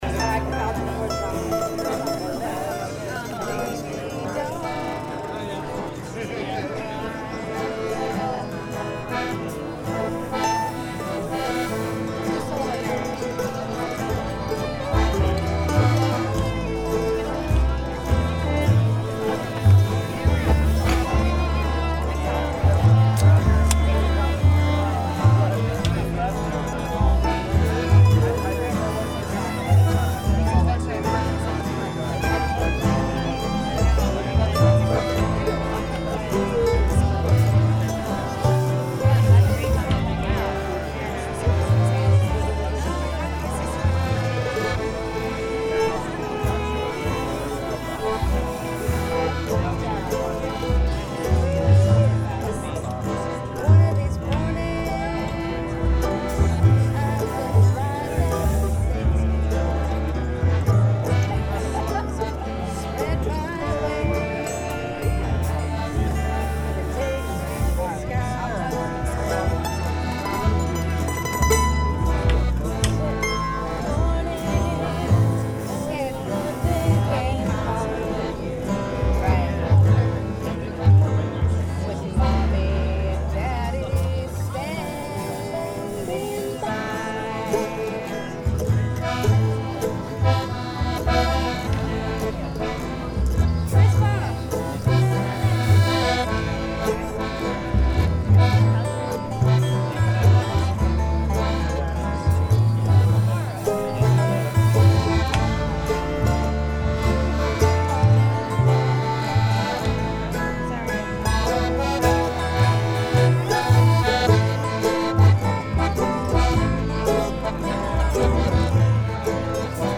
We crossed paths and setup the jam right where we met at the crossroads by the "top of the world" bus.
The crowd began to form and before long we were stirring up folk roots with the aid of the players in the campground!
and many others on banjo, mandolin, fiddle, guitars, and so on...Please email with any more information you may have to add...